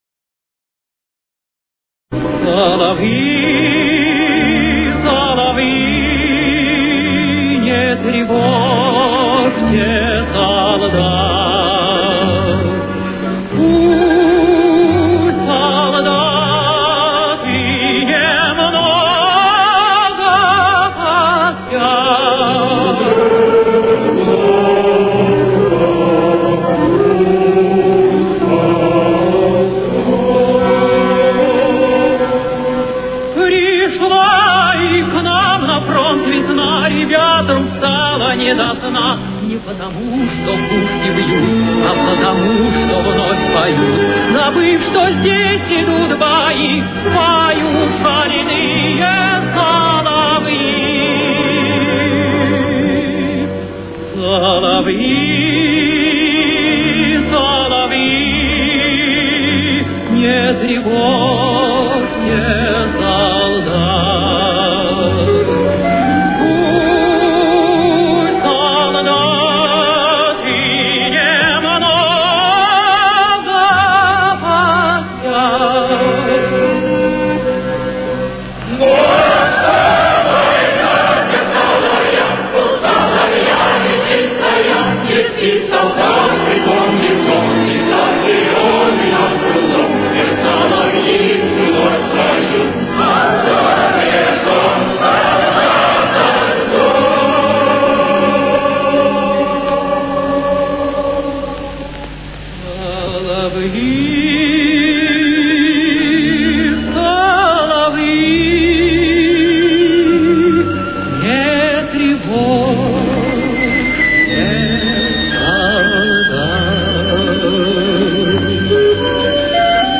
Си минор.